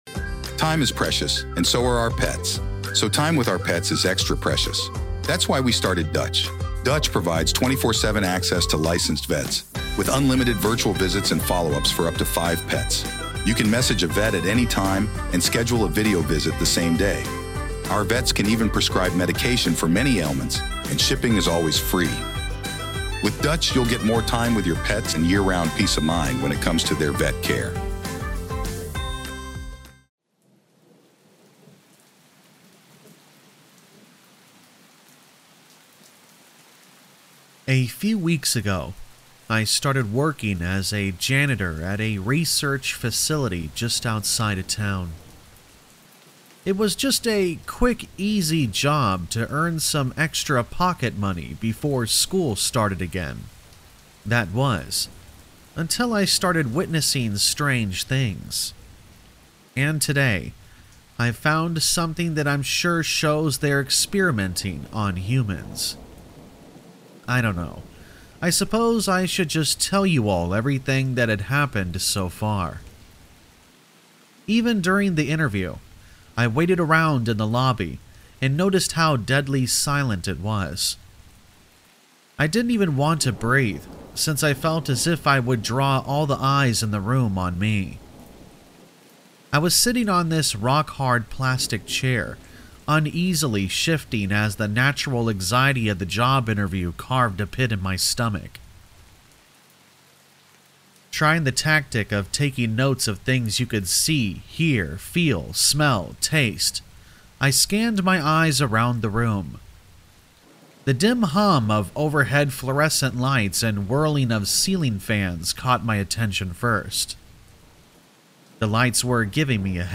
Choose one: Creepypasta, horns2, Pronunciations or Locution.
Creepypasta